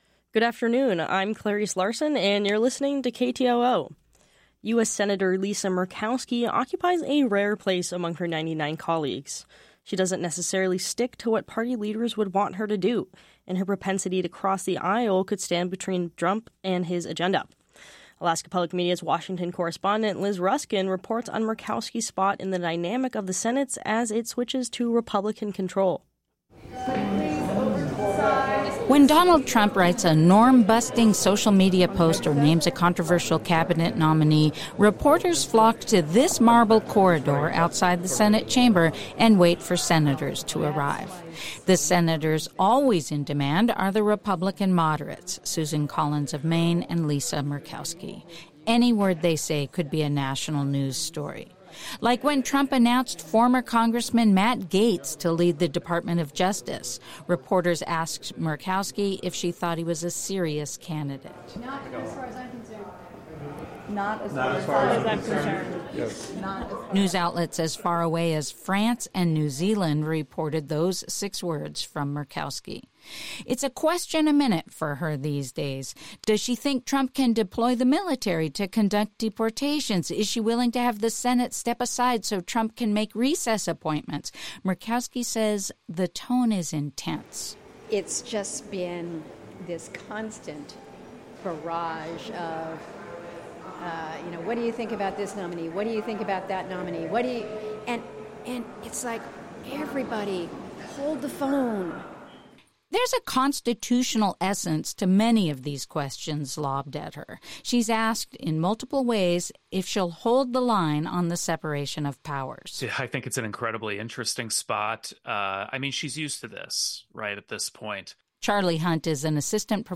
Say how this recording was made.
They blessed the tree and the people at the ceremony with traditional songs.